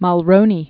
(mŭl-rōnē, -r-), (Martin) Brian Born 1939.